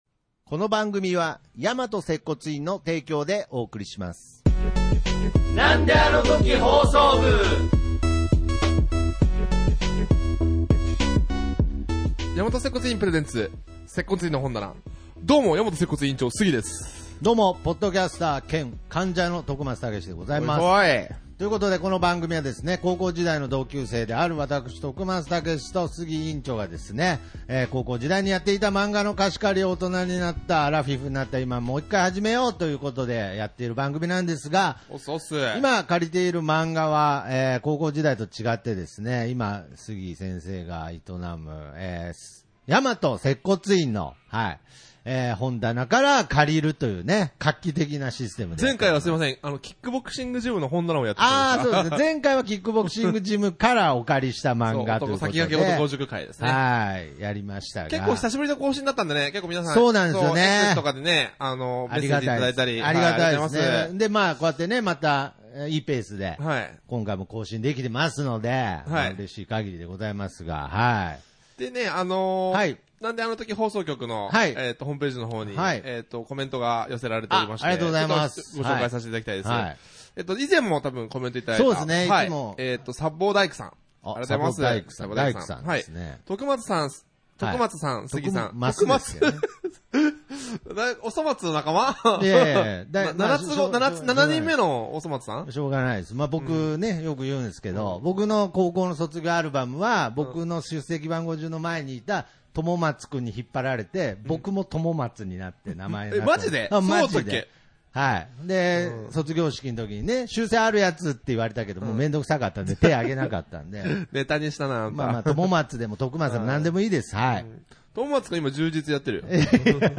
なんであのとき放送局は同級生の中年三人でやっている放送局ごっこ遊びです。